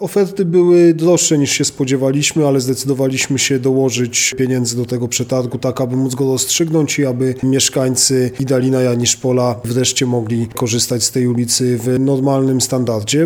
O tym, jak finalnie zakończył się przetarg mówi wiceprezydent Radomia Mateusz Tyczyński: